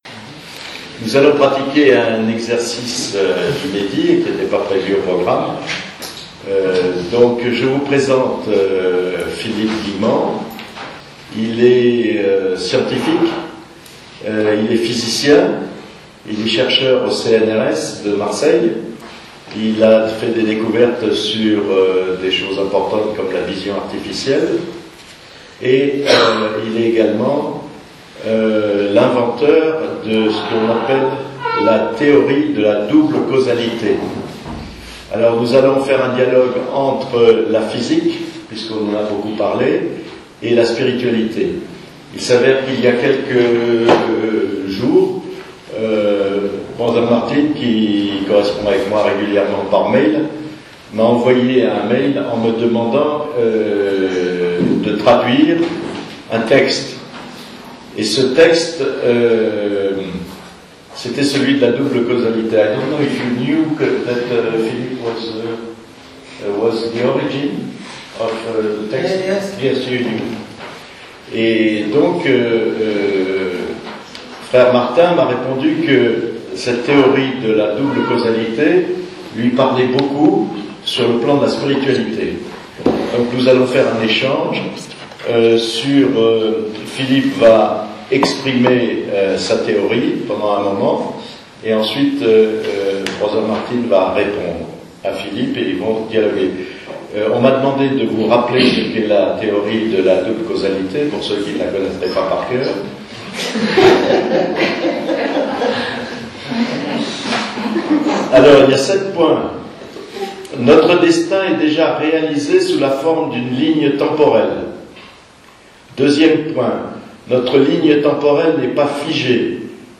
Vous remarquerez que j'étais un peu intimidé et c'est probablement la raison pour laquelle je suis allé jusqu'à parler de "l'amour de Dieu" lorsque j'ai voulu décrire l'être intérieur dont je parle dans mon livre (j'aurais préféré parler de sensation de lumière intérieure).